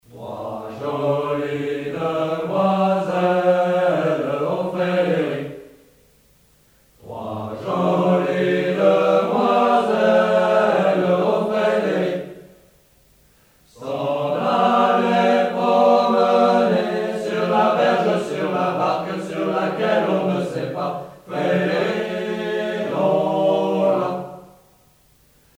gestuel : à marcher
circonstance : militaire